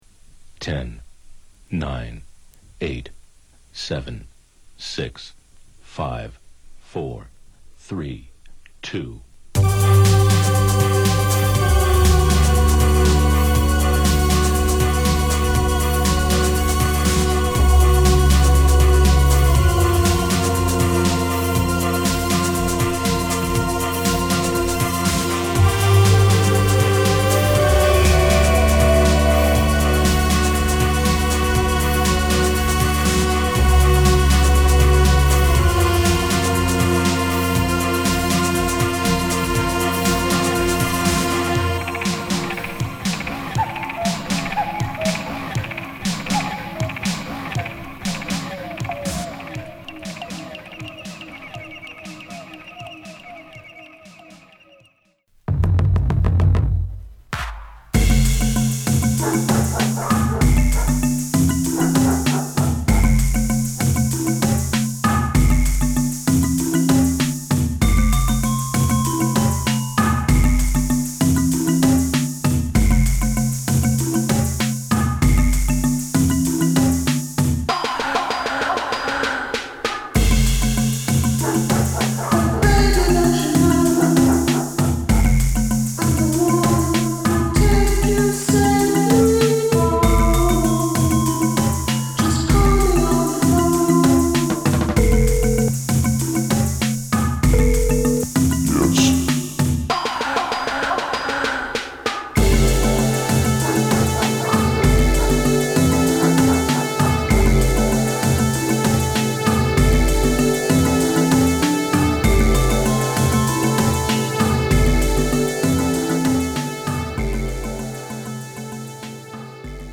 様々な音楽を飲み込んで、解き放たれた 唯一無二のエレクティックでシネマティックなサウンドスケープ！